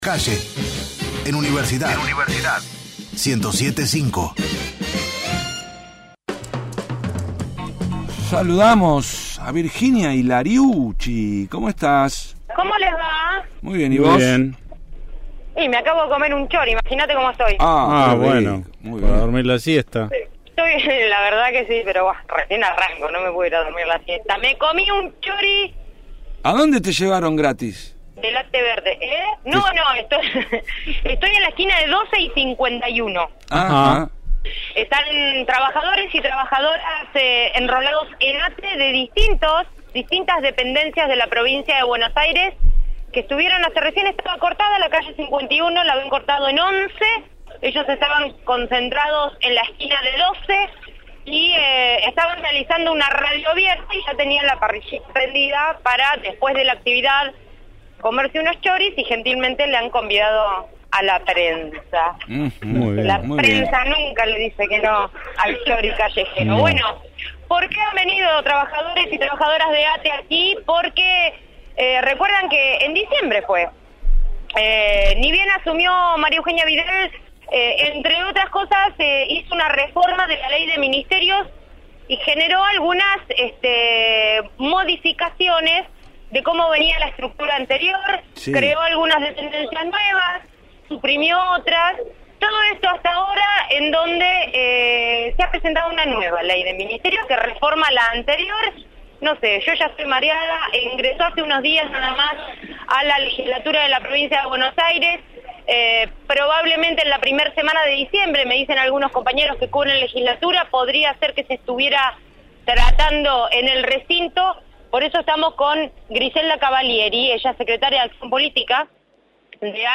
desde 11 y 51 con la radio abierta de ATE para debatir la Ley de Ministerio